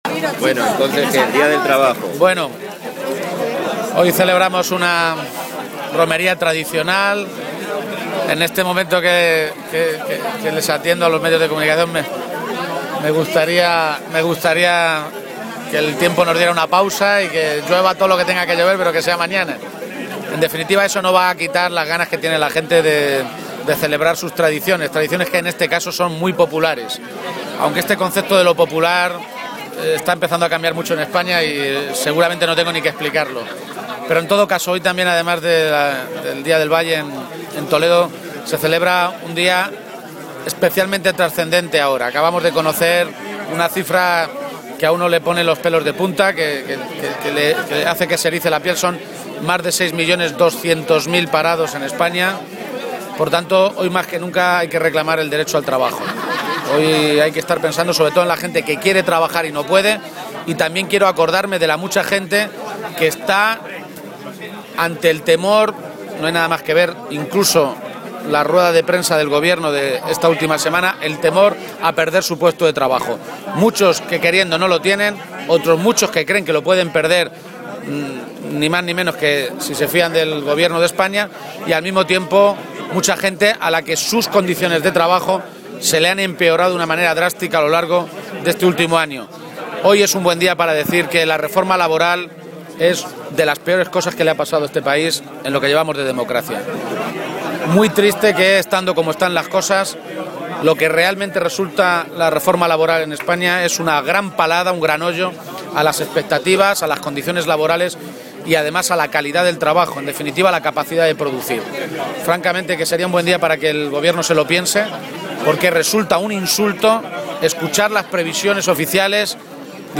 Page se pronunciaba así, esta mañana, en la tradicional Romería del Valle de Toledo, a preguntas de los medios de comunicación.
Cortes de audio de la rueda de prensa